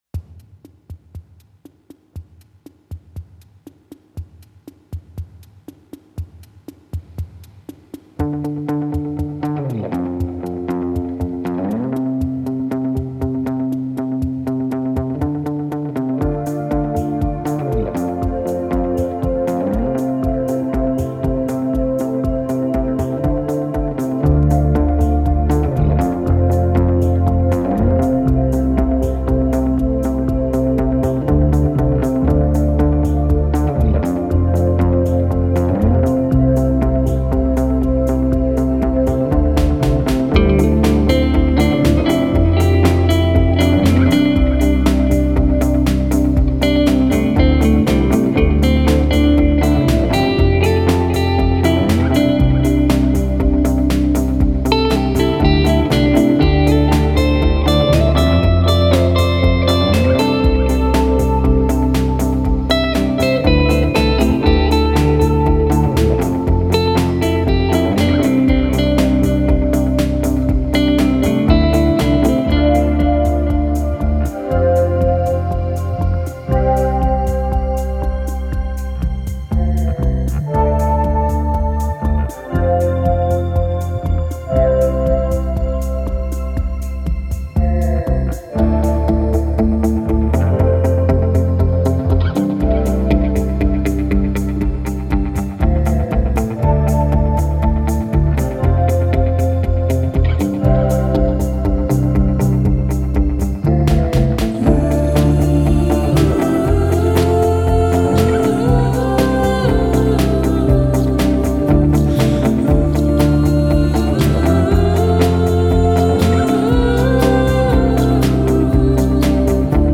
The songs also play with merging music and field recordings